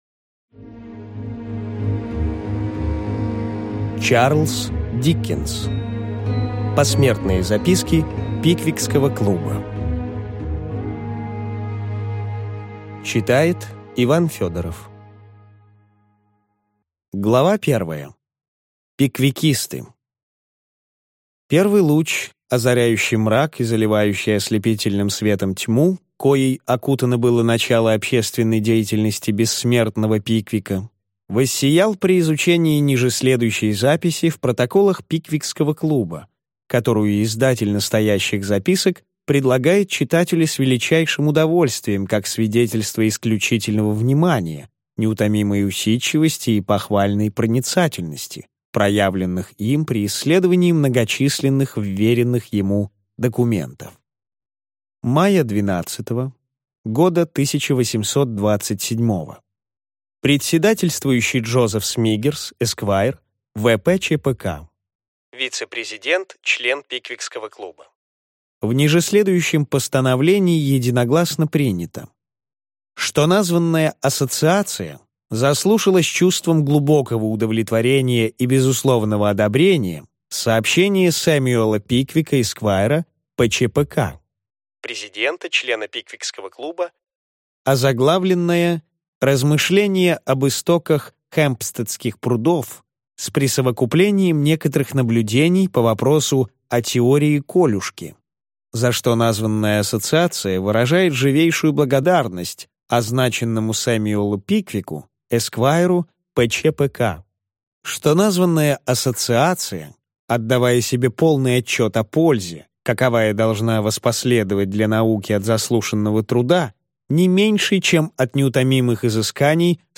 Аудиокнига Посмертные записки Пиквикского клуба | Библиотека аудиокниг